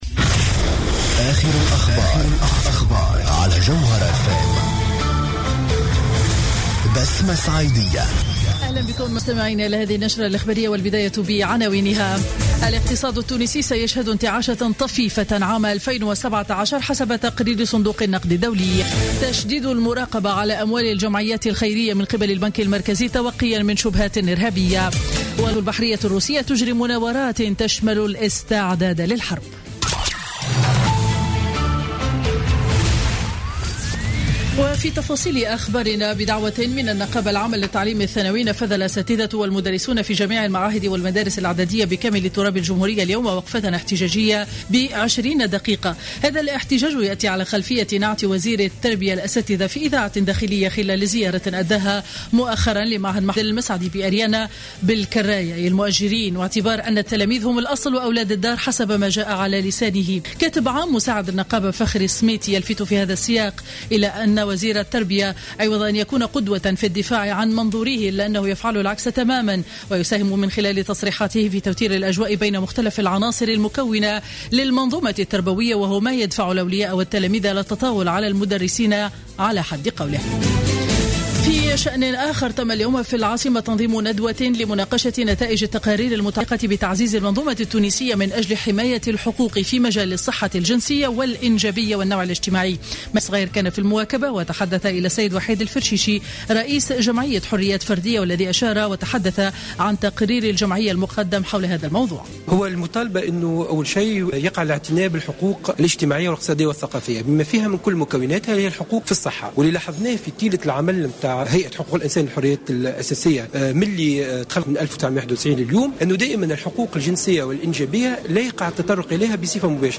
نشرة أخبار منتصف النهار ليوم الاثنين 07 ديسمبر 2015